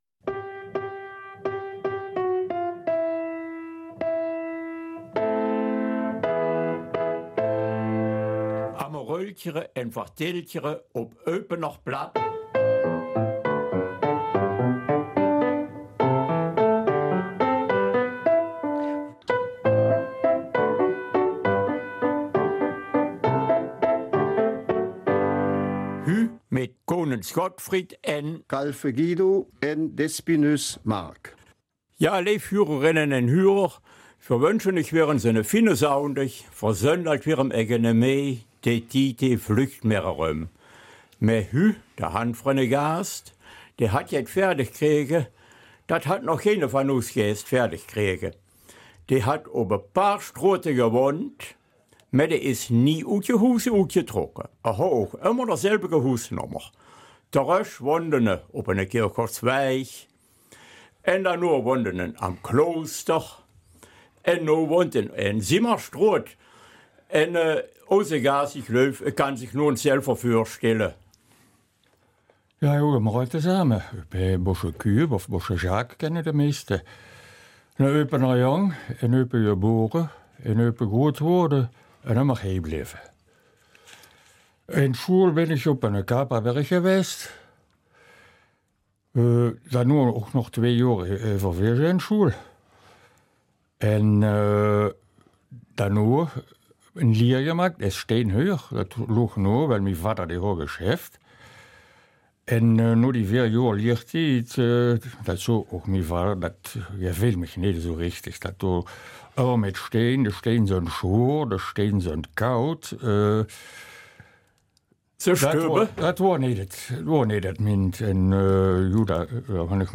Eupener Mundart
Es wird spannend in diesem Interview!